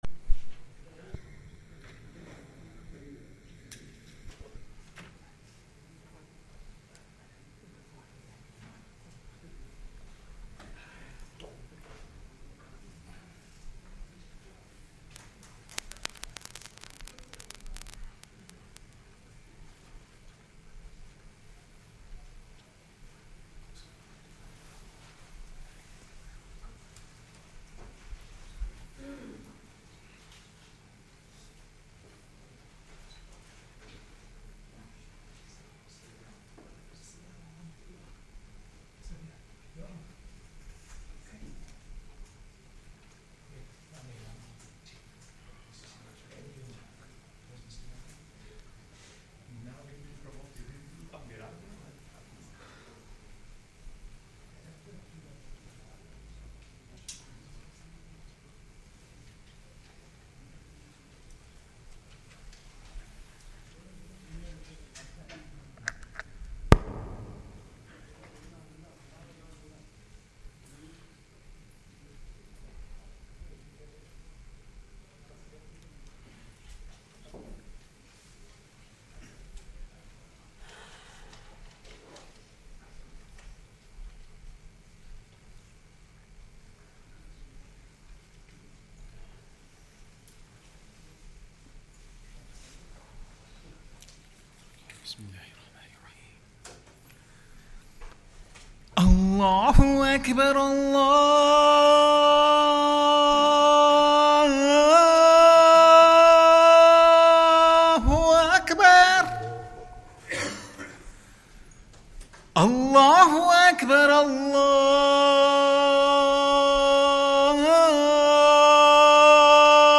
Masjid Al Farouq | Jumuah